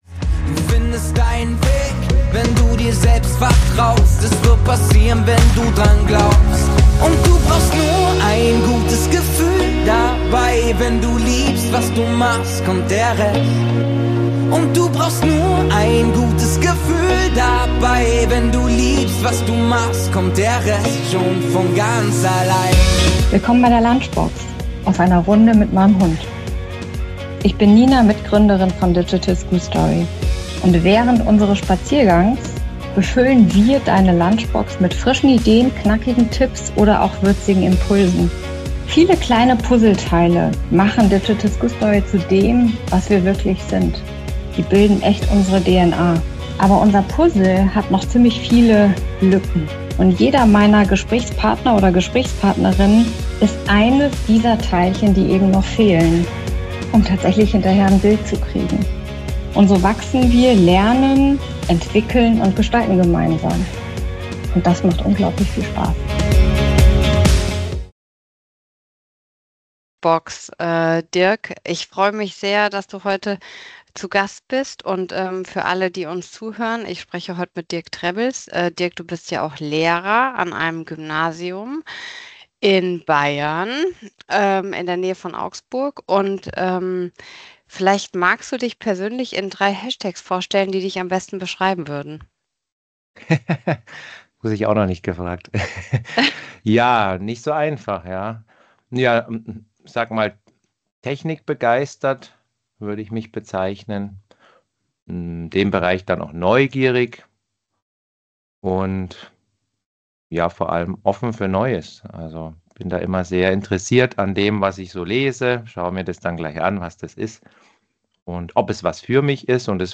Ein Gespräch über Neugier, Mut zur Veränderung und die Schule von morgen.